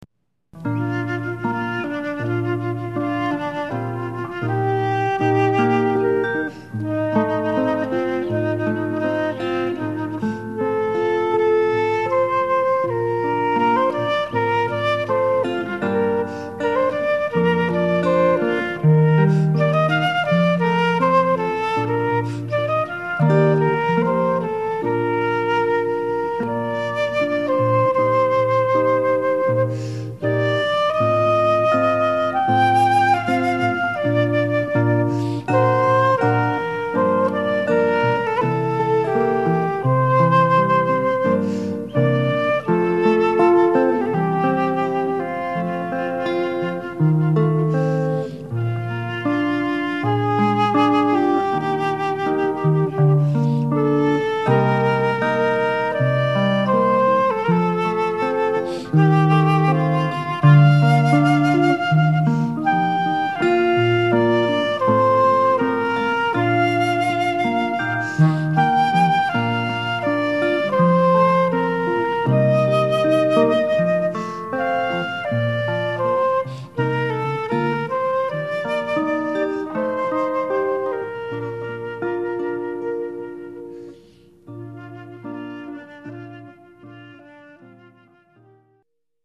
Wedding Duo-Guitar & Flute